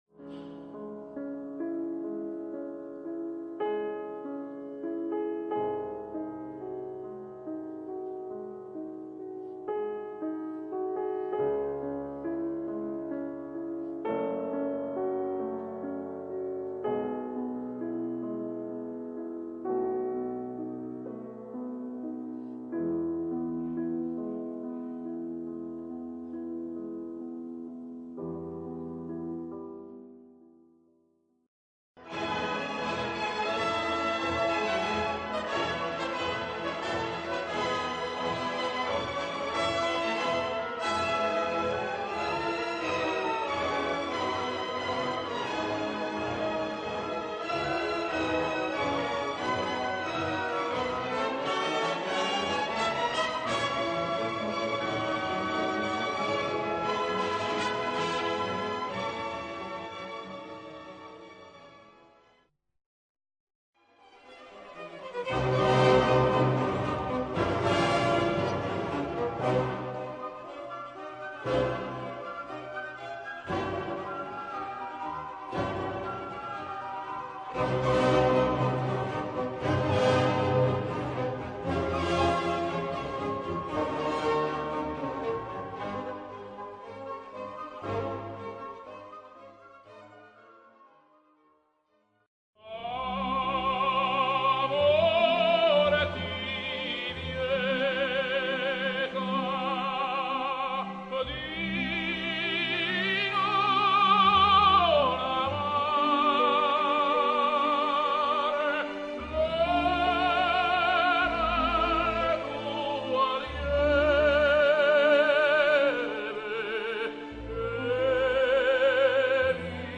L'audio propone sette frammenti di musica romantica.
stili_romantici.mp3